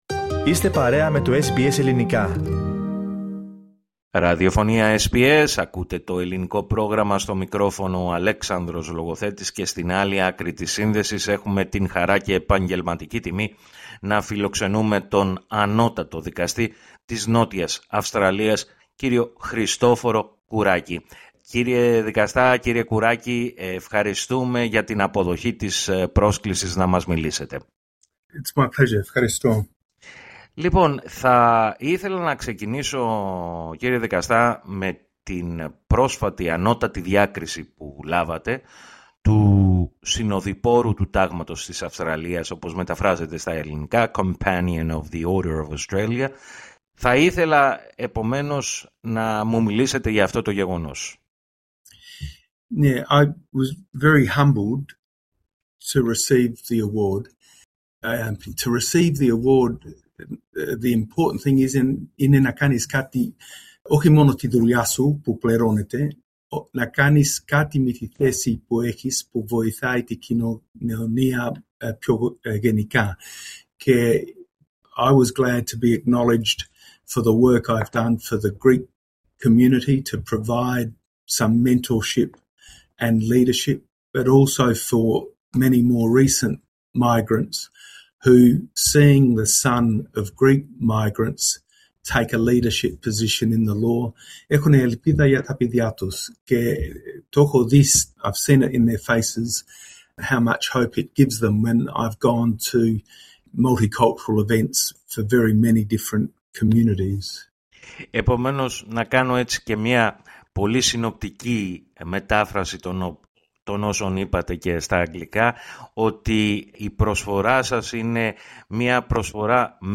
Με αυτήν την αφορμή, μίλησε στο Ελληνικό Πρόγραμμα της ραδιοφωνίας SBS.